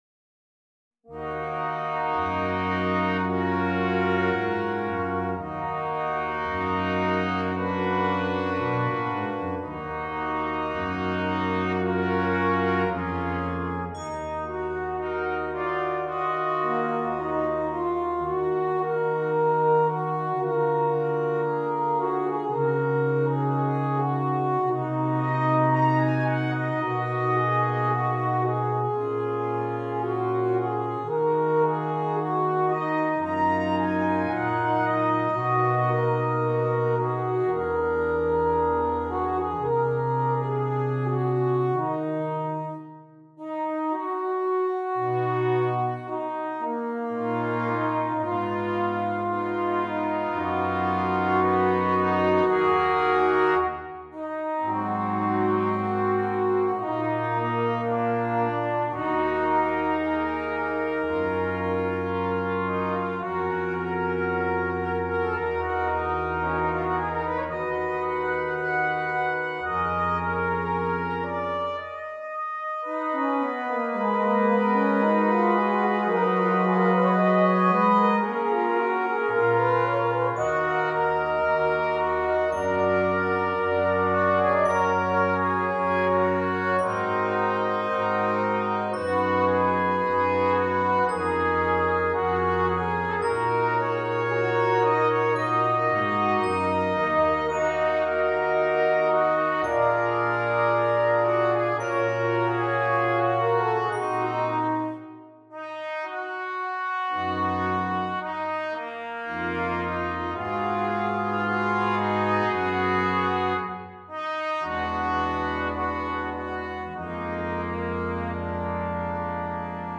Brass Quintet and Solo Trumpet
Traditional Spiritual
This is a solo for flugel horn or trumpet.